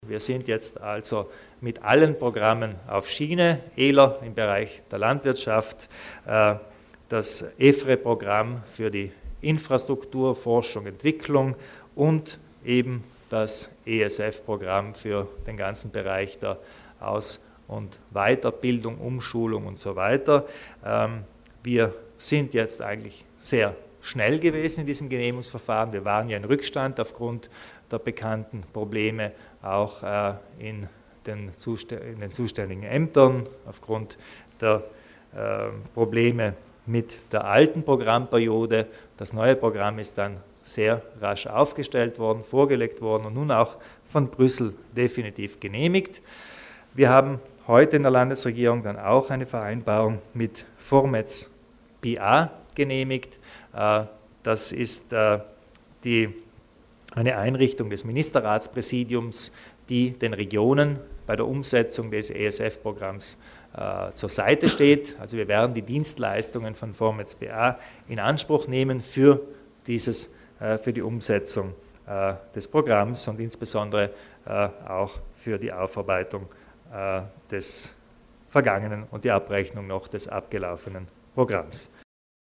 Landeshauptmann Kompatscher zu den Neuigkeiten beim Europäischen Sozialfonds